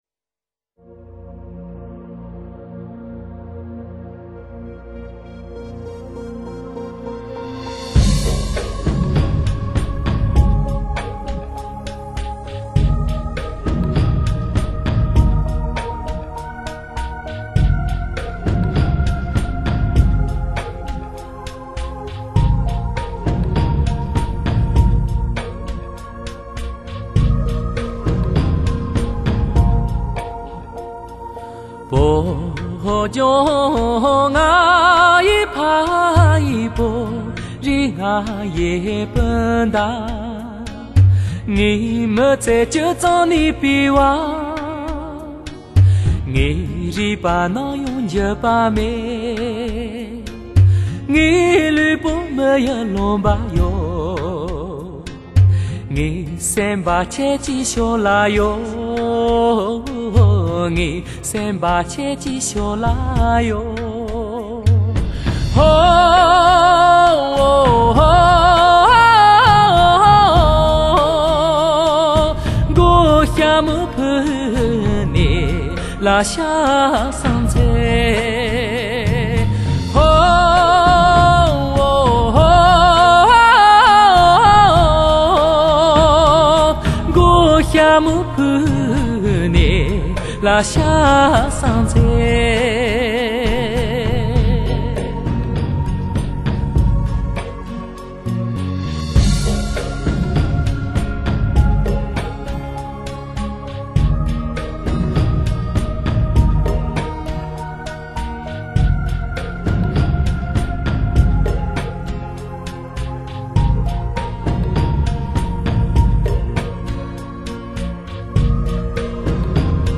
他的音乐拥有独特的民族通俗风格及浓郁的雪域风情。